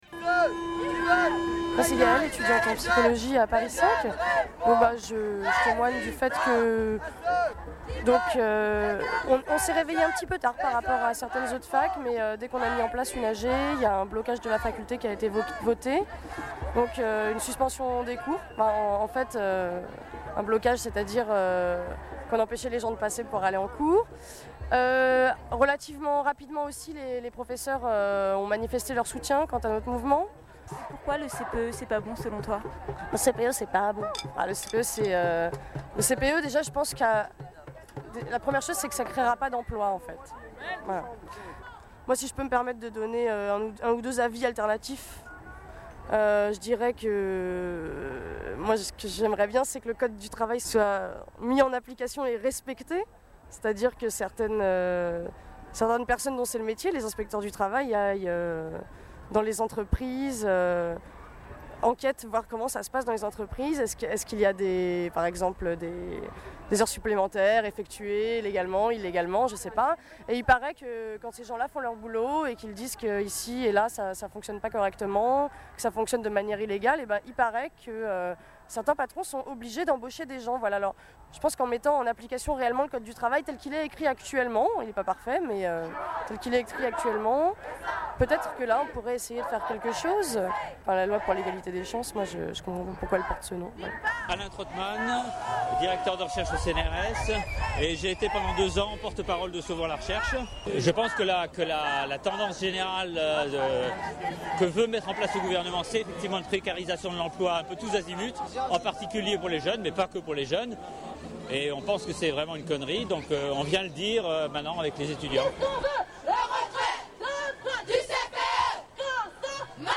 Reportages